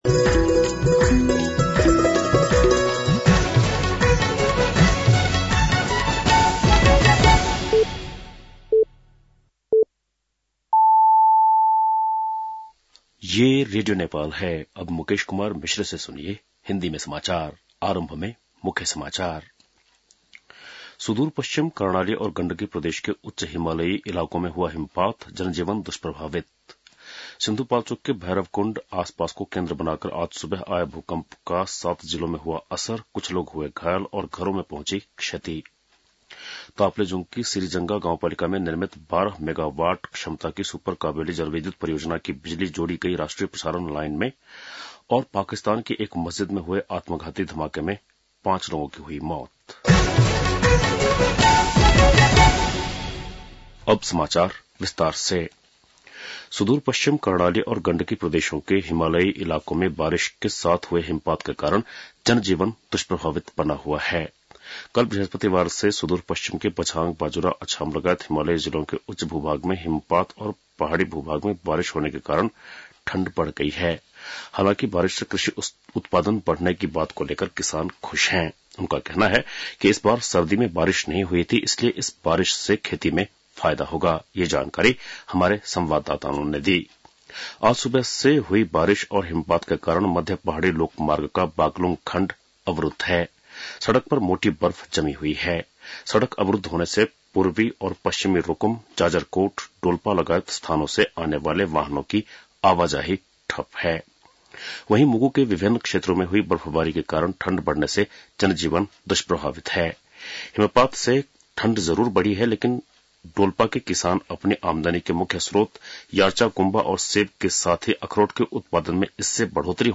बेलुकी १० बजेको हिन्दी समाचार : १७ फागुन , २०८१